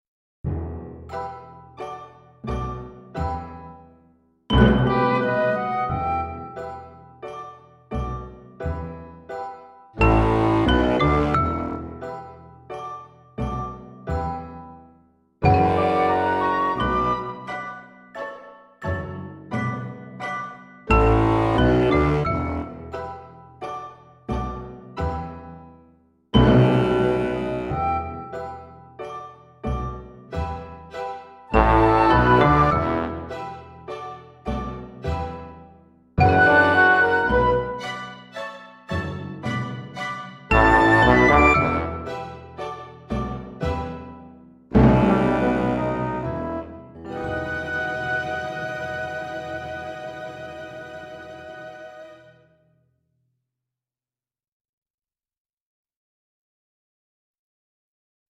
VS Witches' Cauldron (backing track)